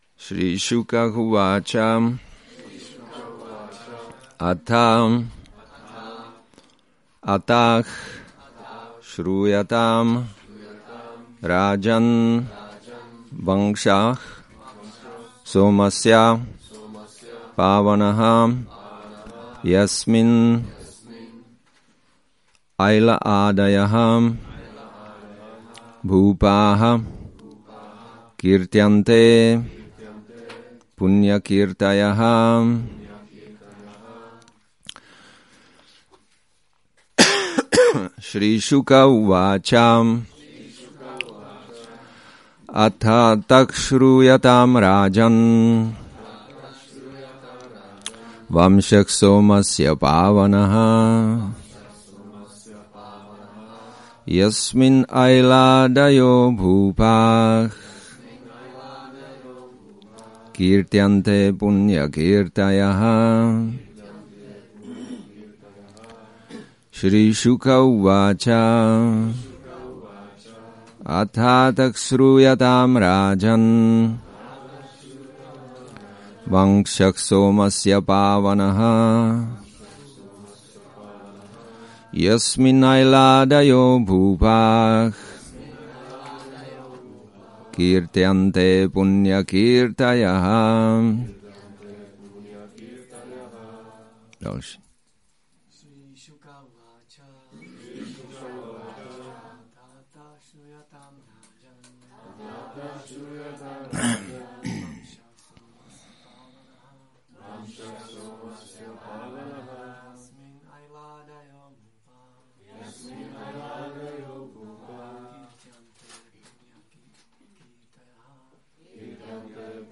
Šrí Šrí Nitái Navadvípačandra mandir
Přednáška SB-9.14.1